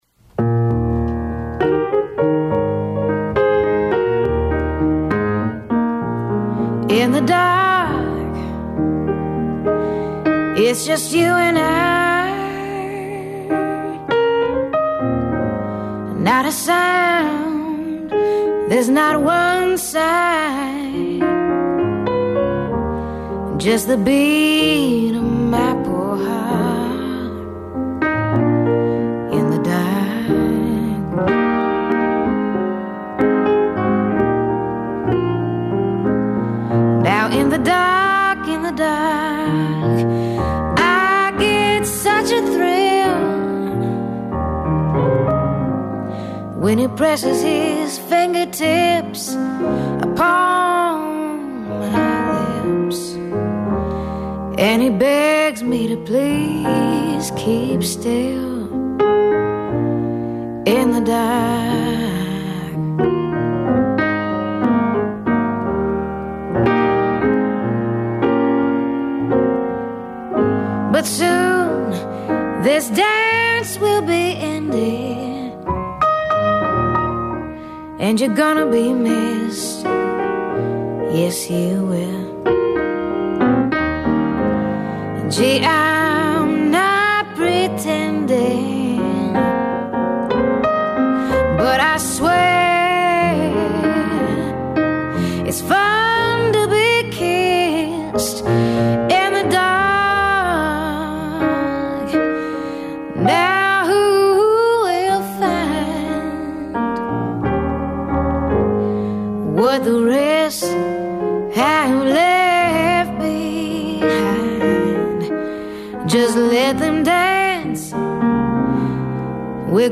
FM Broadcast